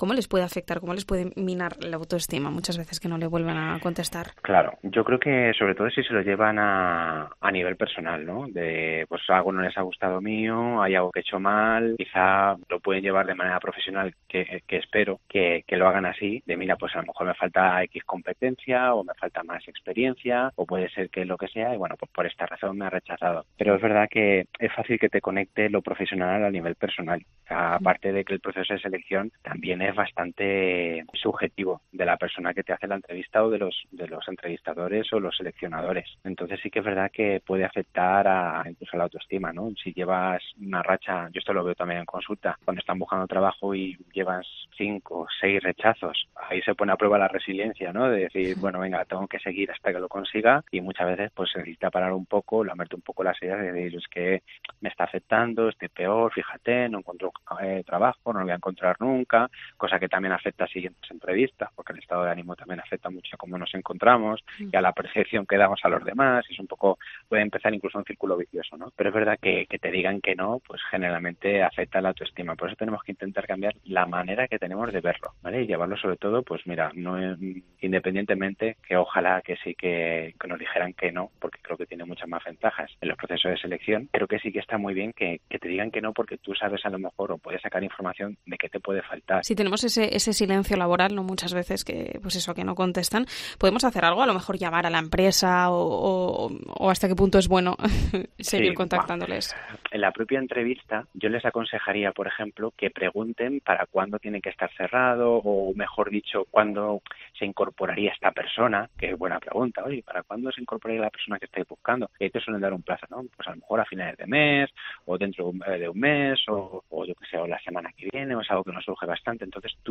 psicólogo experto en RRHH, nos explica cómo nos afecta que nos rechacen